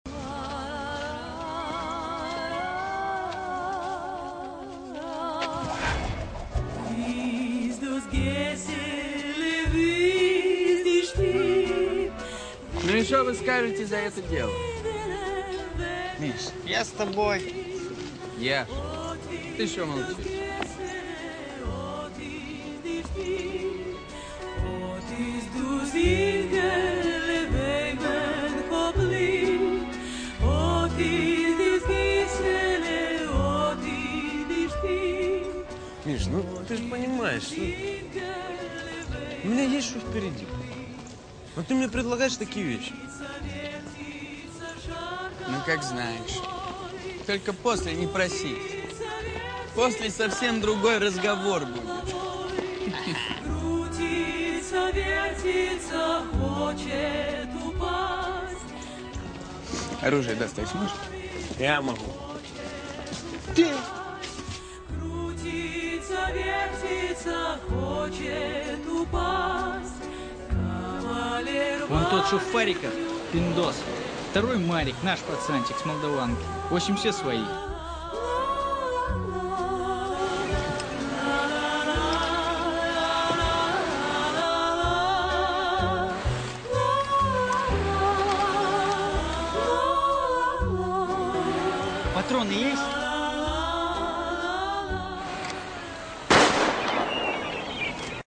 Теги: музыка, нежная, лето, закат